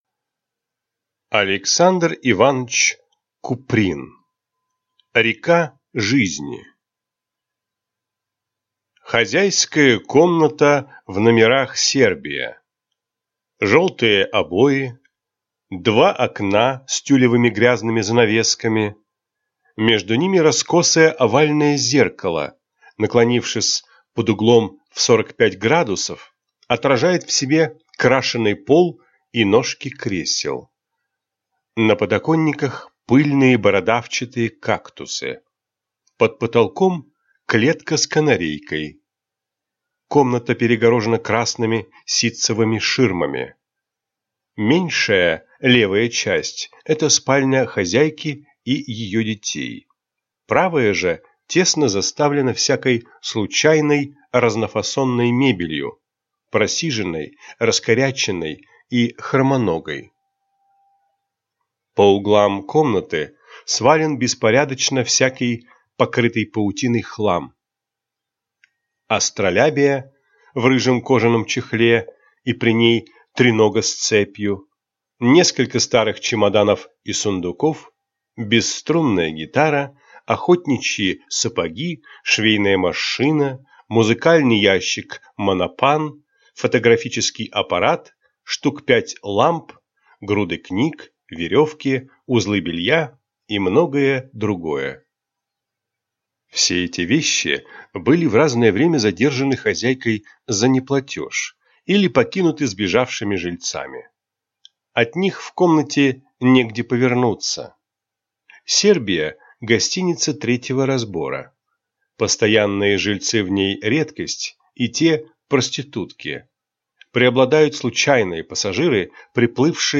Аудиокнига Река жизни | Библиотека аудиокниг
Прослушать и бесплатно скачать фрагмент аудиокниги